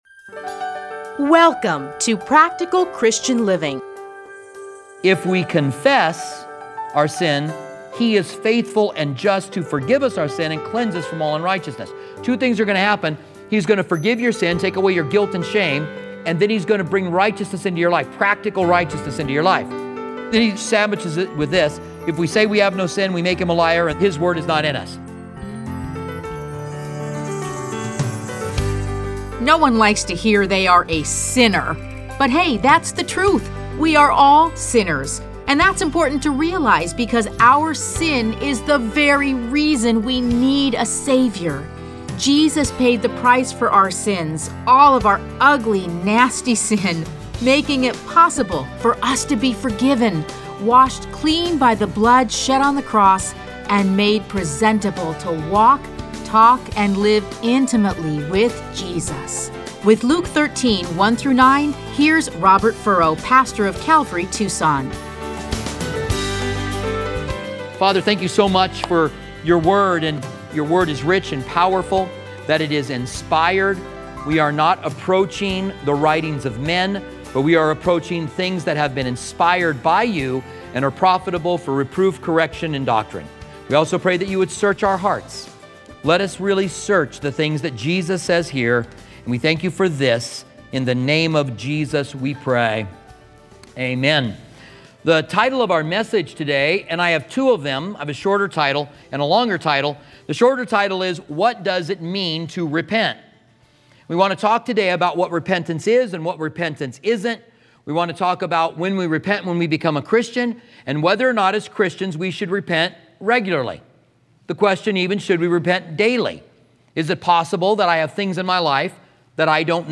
Listen to a teaching from Luke 13:1-9.